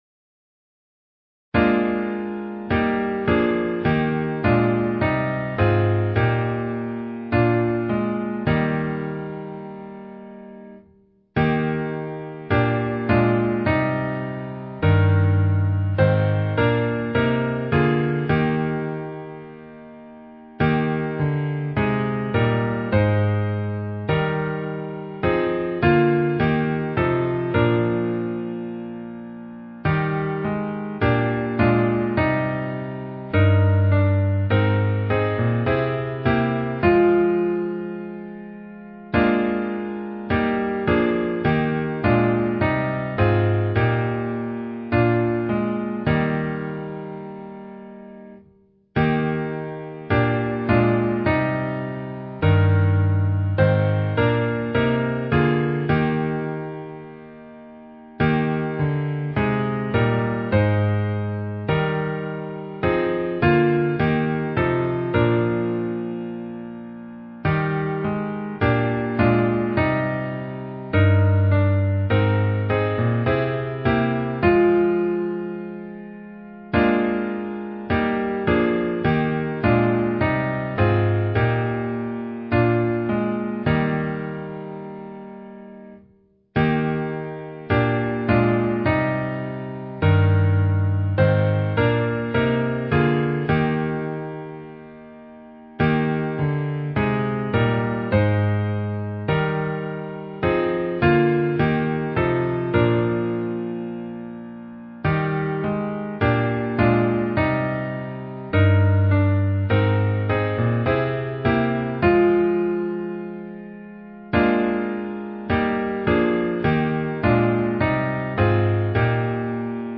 • Key: E♭